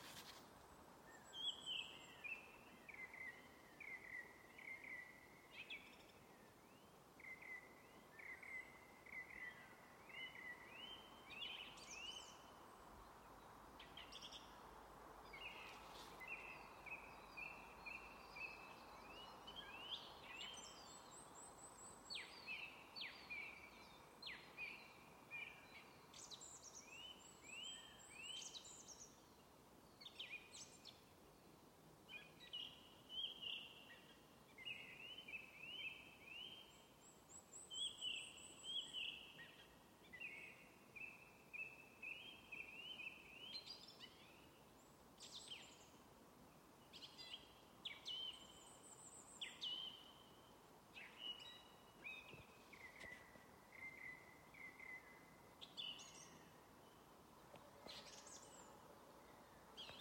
Dziedātājstrazds, Turdus philomelos
Administratīvā teritorijaStrenču novads
StatussDzied ligzdošanai piemērotā biotopā (D)